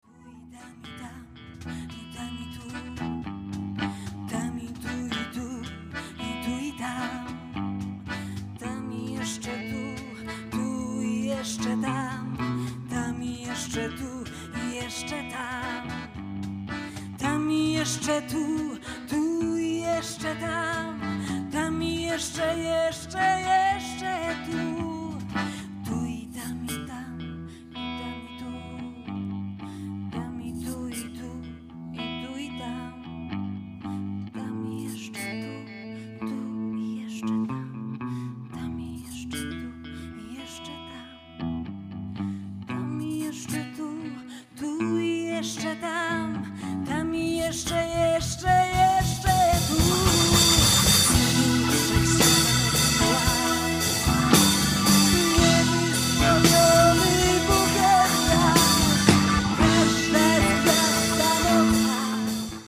FRAGMENTY KONCERTU – SPEKTAKLU POETYCKiEGO „ŚWIĘTE WĘDROWANIE” (FESTIWAL „STACHURA”, 2014):